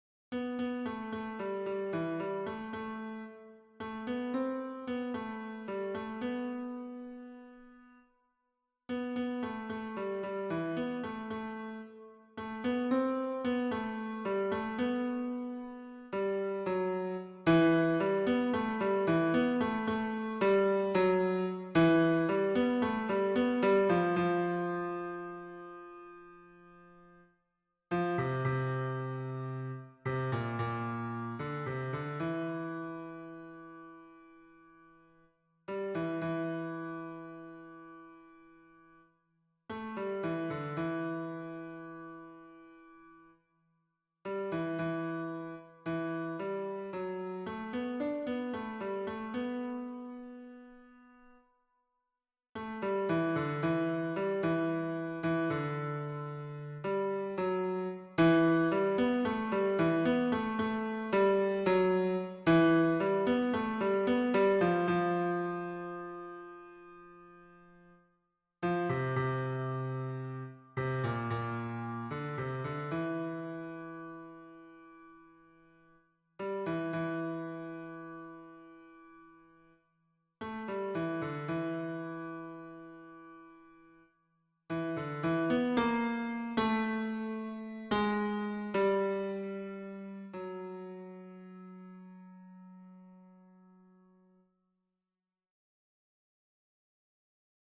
Hommes